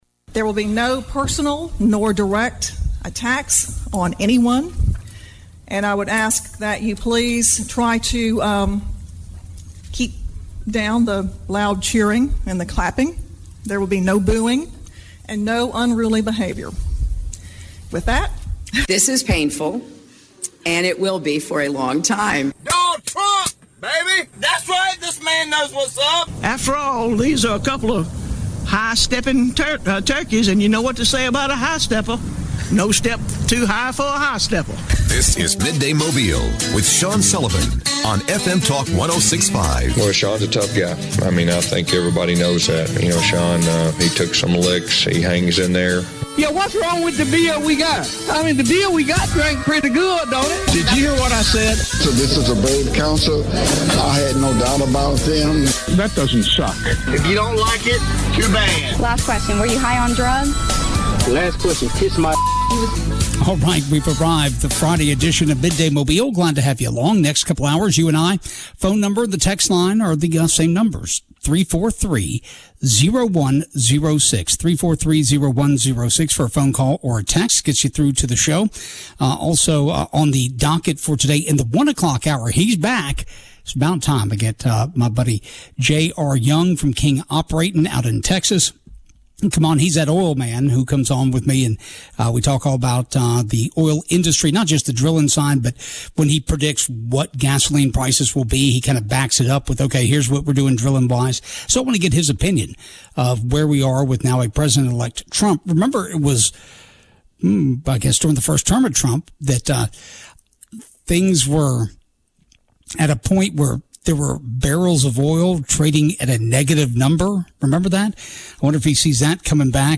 Drones - Voicemails about Drones - Midday Mobile - Friday 12-13-24